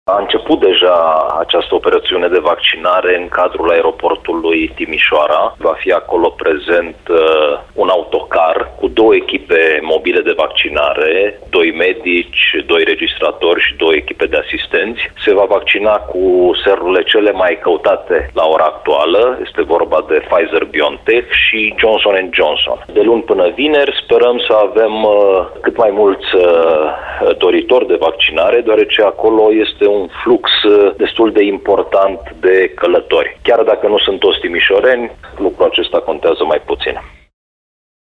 Programul de administrare a serului este stabilit între orele 10,00 și 18,00 spune, subprefectul de Timiș Ovidiu Drăgănescu: